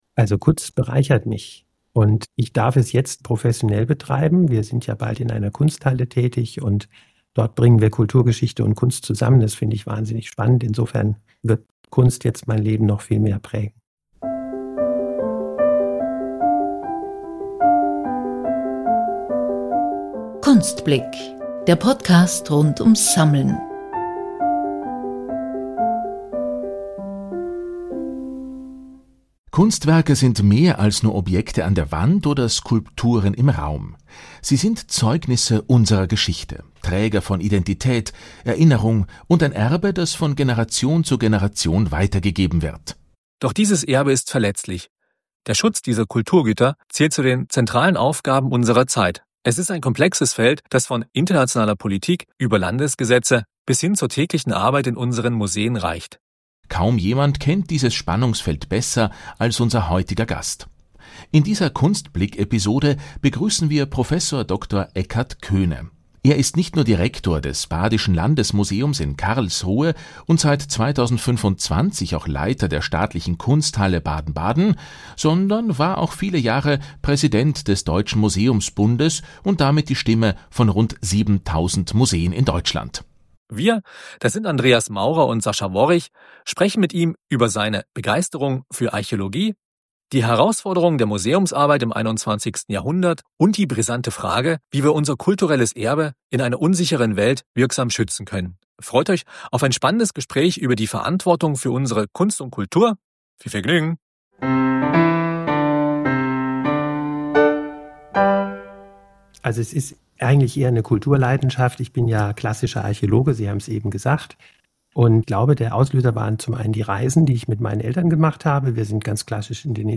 Freut euch auf ein spannendes Gespräch über die Verantwortung für unsere Kunst und Kultur.